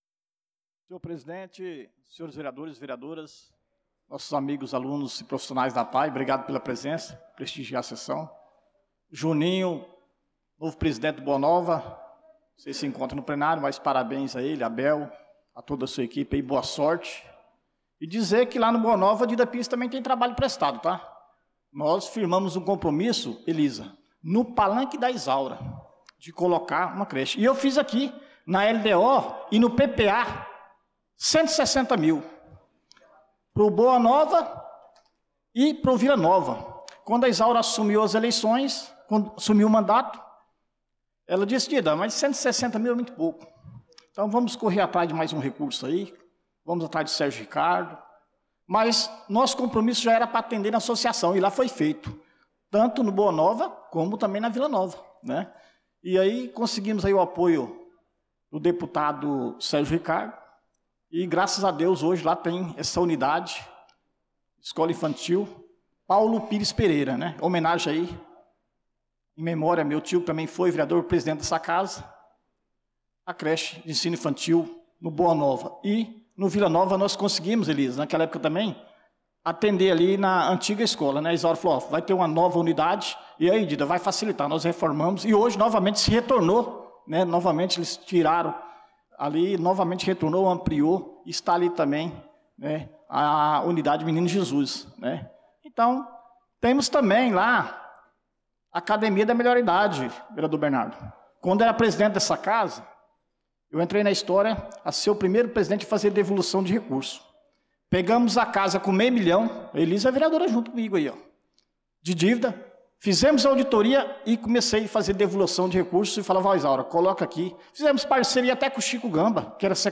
Pronunciamento do vereador Dida Pires na Sessão Ordinária do dia 26/05/2025